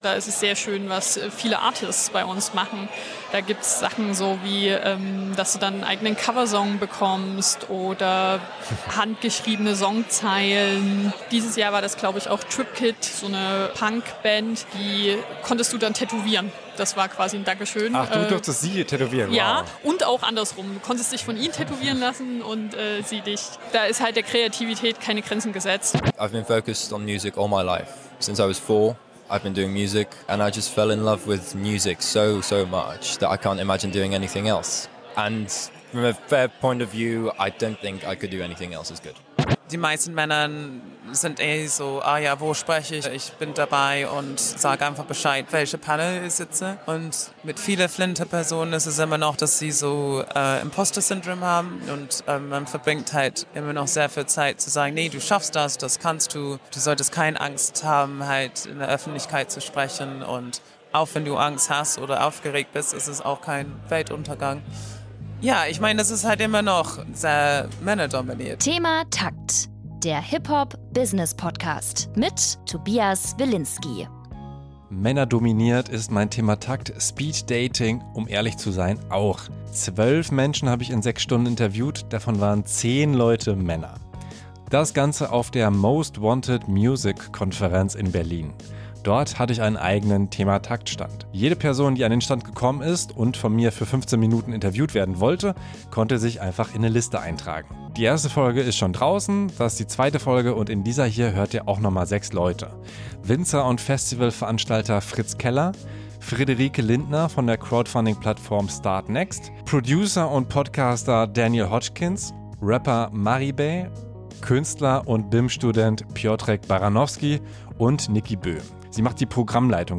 In Interviews geben Manager:innen, Promoter:innen, Producer:innen und Artists Einblick in die Musikindustrie, das HipHop-Business und ihren Lebensweg. Es geht um Veränderungen in der Musikbranche, Musikbusiness-News und Tipps für Musikschaffende.